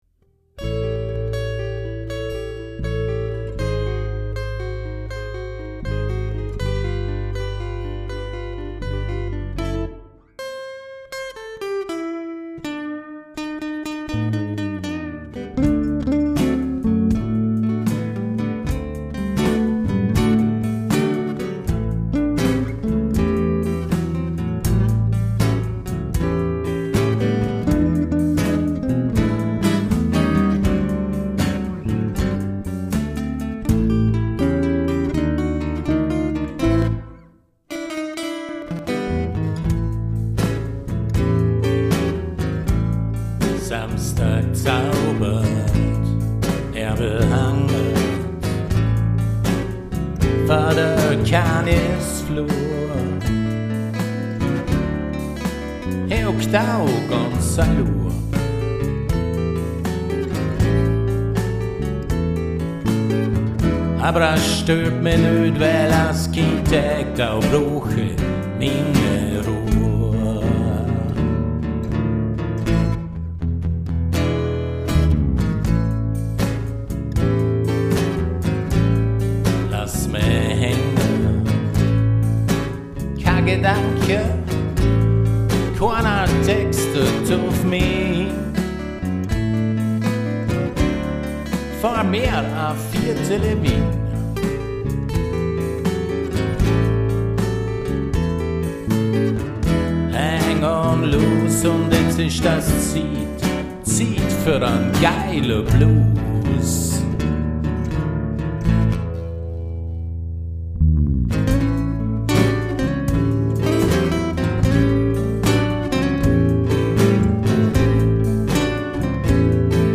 Handgemachte Musik im Bregenzerwälder-Dialekt